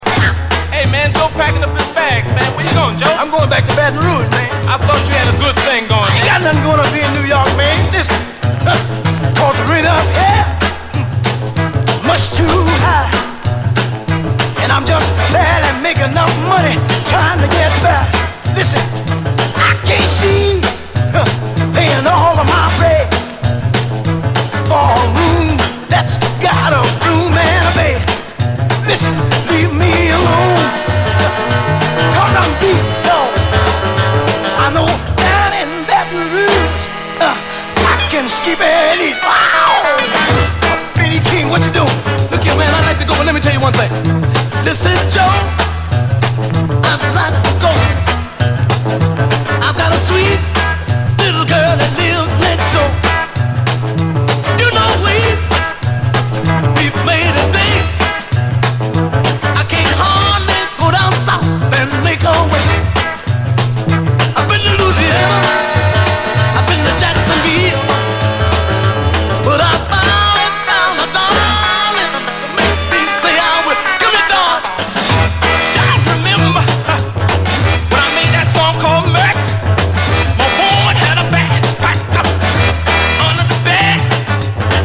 タダのストリングス・グループではナシ！
幽玄ジャズ・ファンク
レアグルーヴ・クラシック
ヒンヤリとサイケデリックでブッ飛んだアレンジがヤバい、マッド・ファンク傑作！シタール入りでさらにイカれたジャズ・ファンク